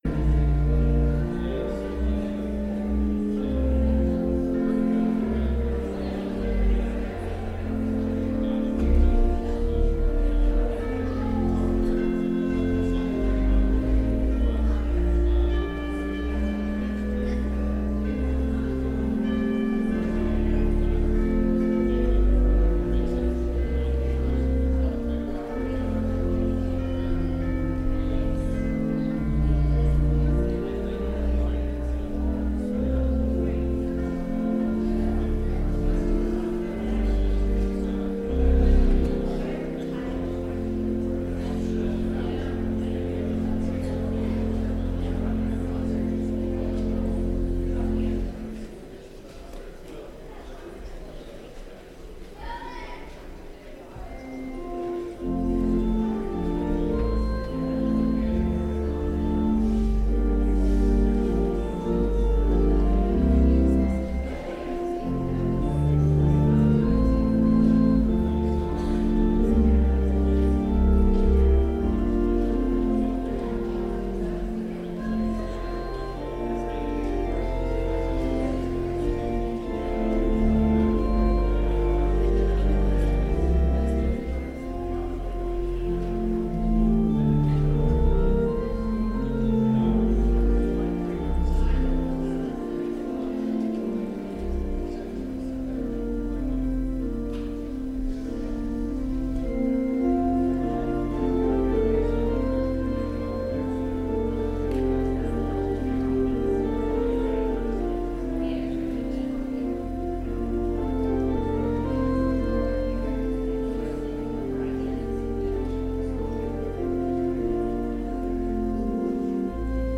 sermon1_26_20.mp3